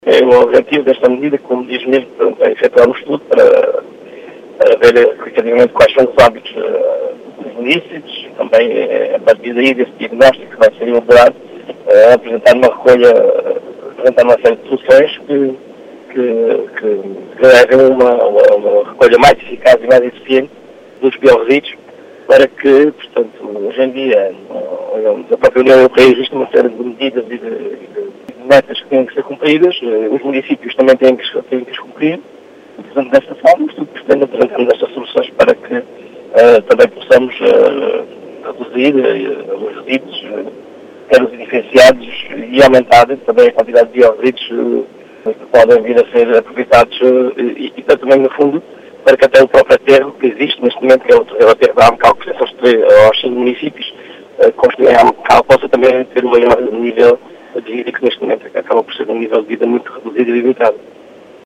As explicações são de João Português, presidente da Câmara de Cuba.